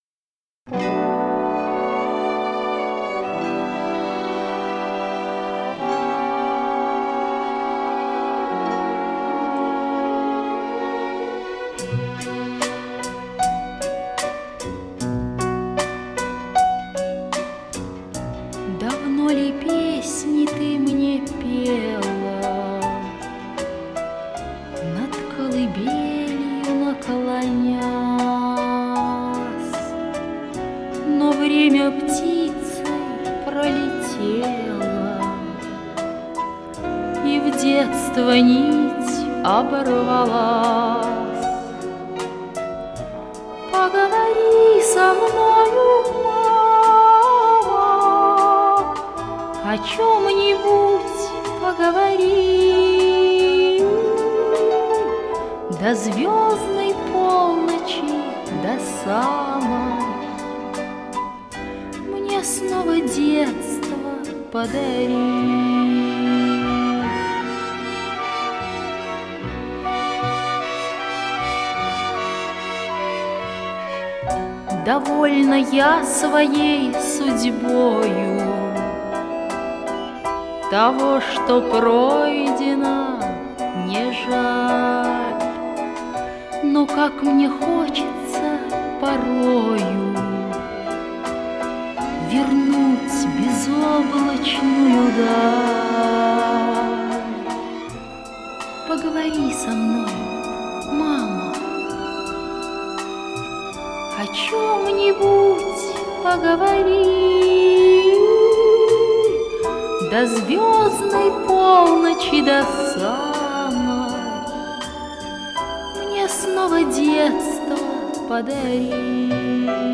Вот еще неплохое стерео